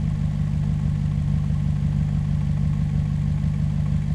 v8_13_idle.wav